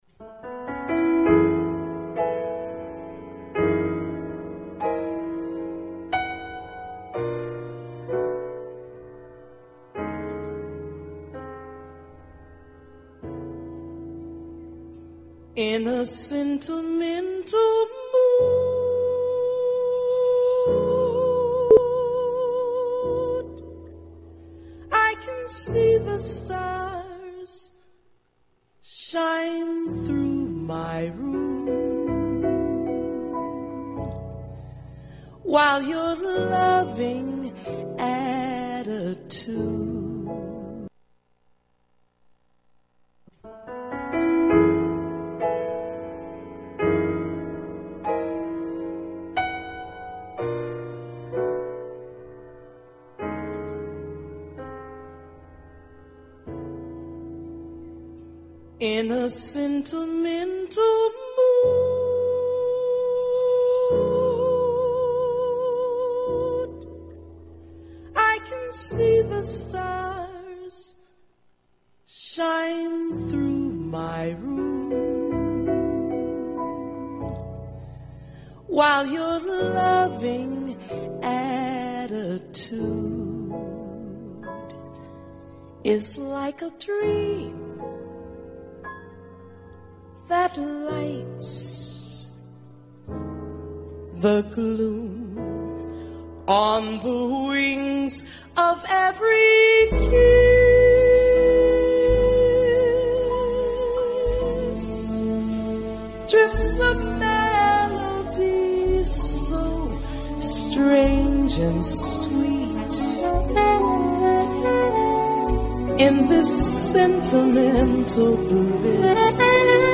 Intonata come poche davvero.